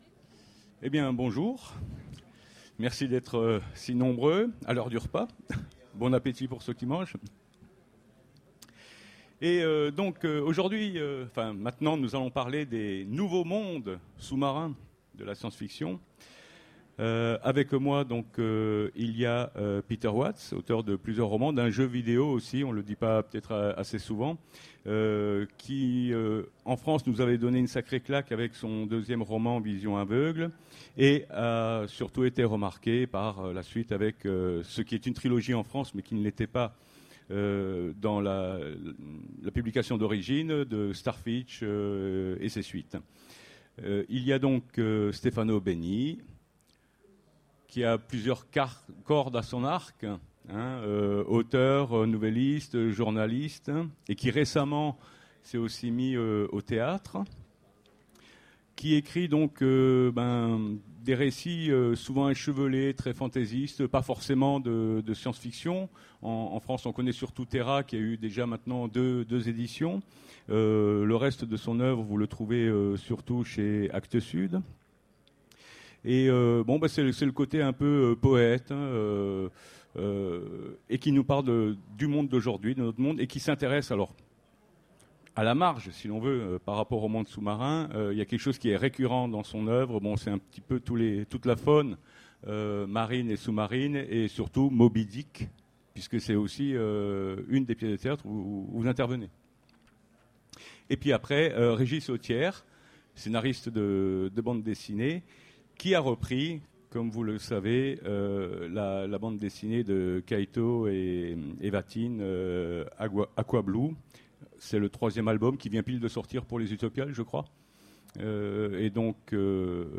Utopiales 13 : Conférence Les nouveaux mondes sous-marins dans la SF